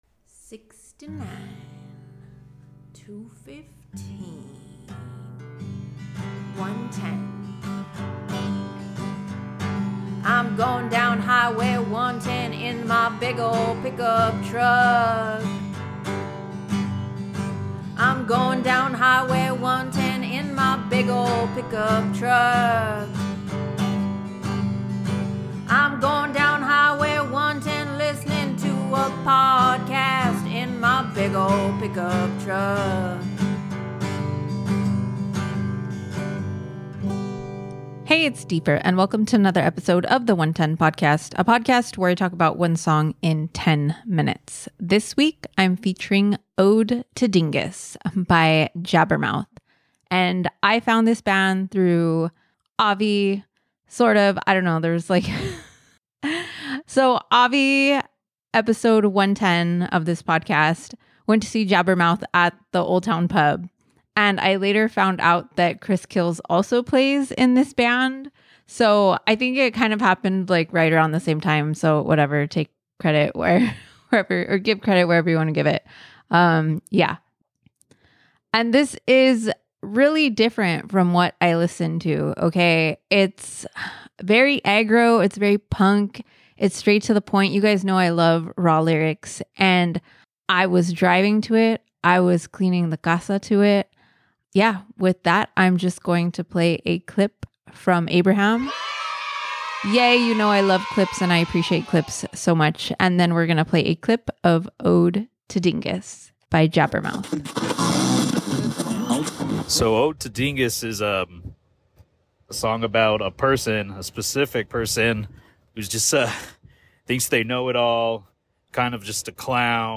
just so happens to be their guitarist.